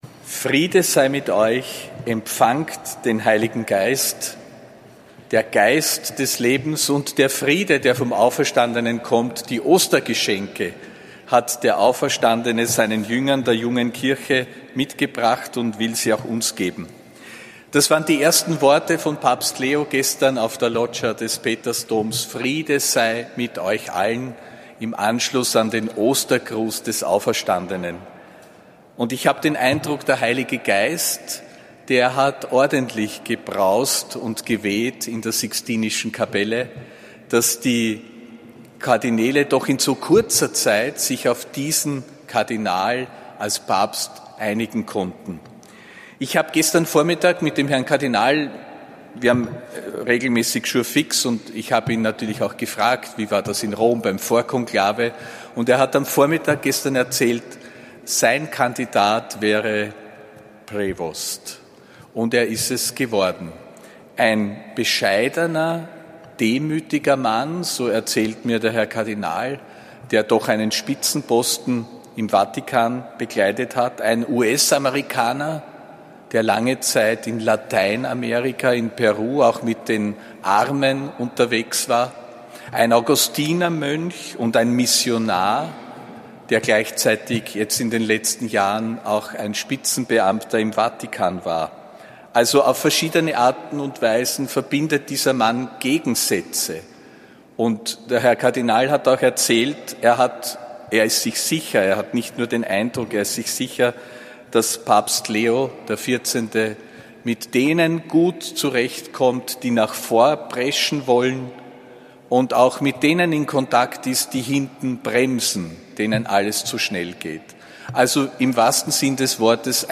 Predigt beim Gebet für Papst Leo XIV. (9. Mai 2025)
Predigt des Apostolischen Administrators Josef Grünwidl beim Gebet